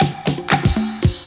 DRUMFILL11-L.wav